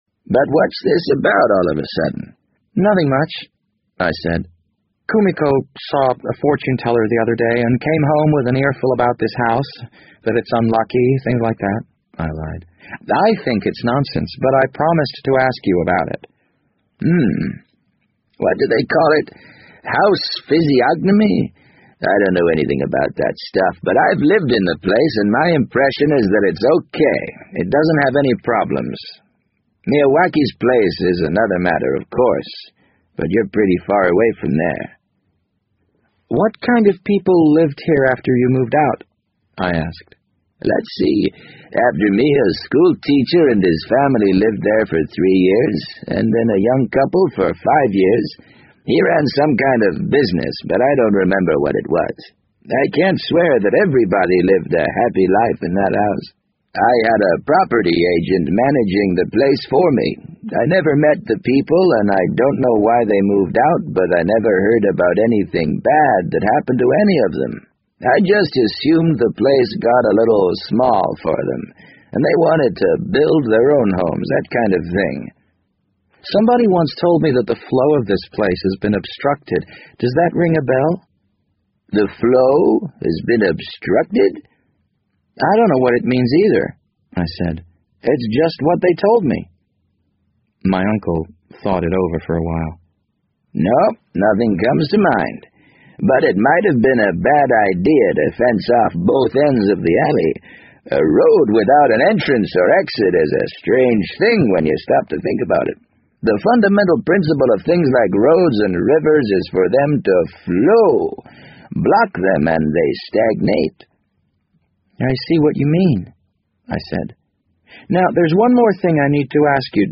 BBC英文广播剧在线听 The Wind Up Bird 008 - 1 听力文件下载—在线英语听力室